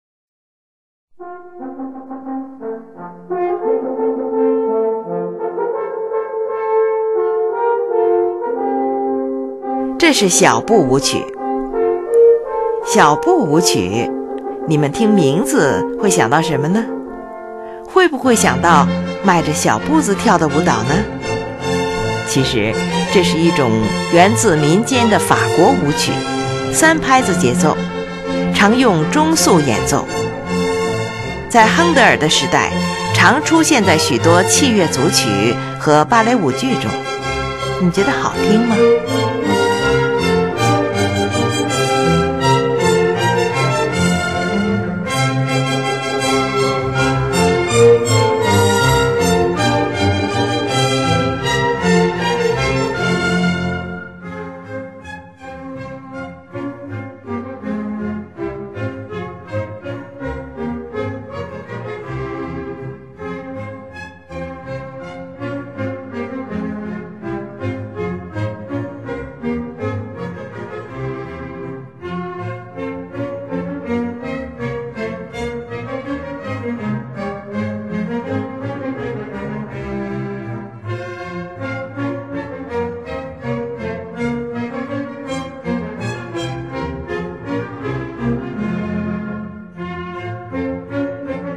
你们可别误会了，以为它就一定是号与笛子吹奏出来的，它其实是16世纪初的一种三拍子的舞蹈音乐。
是一部管弦乐组曲。
乐器使用了小提琴、低音提琴、日耳曼横笛、法兰西横笛、双簧管、圆号、小号等